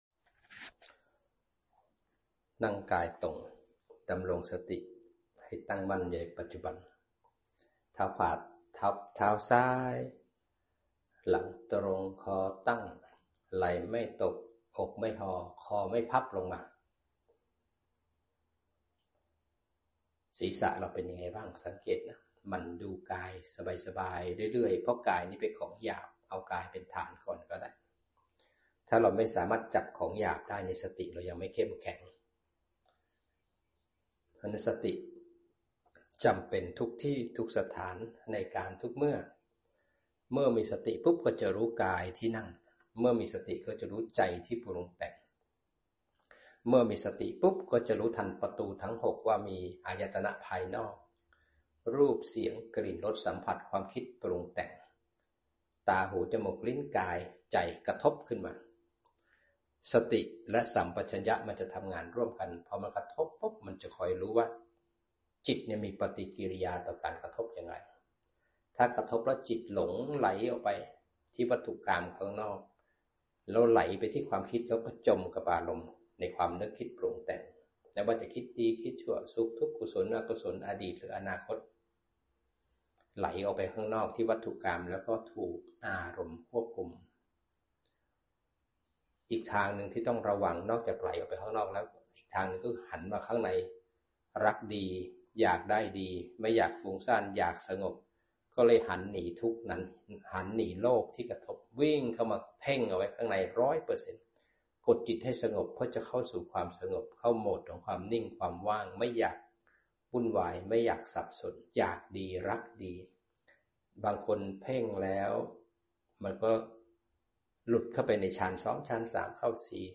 อจ นำจิตปฏิบัติทั้งสมถะ และวิปัสสนา